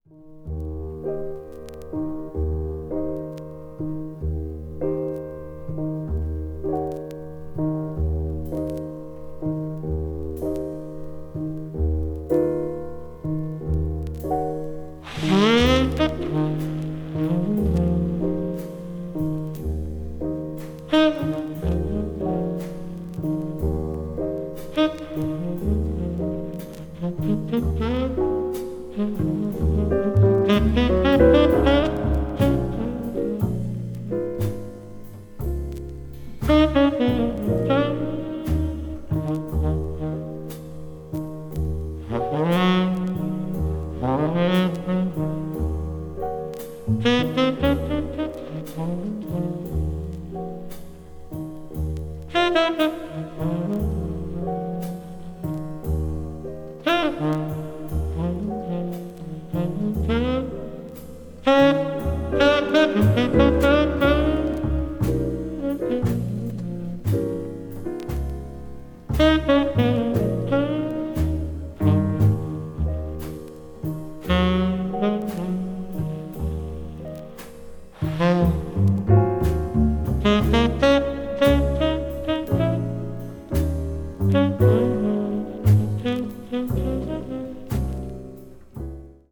そのタイトルのとおり、全編非常にしっとりとしたムードでゆっくりと展開するジャズ・バラードを全編で演奏した作品。
bop   jazz ballad   modern jazz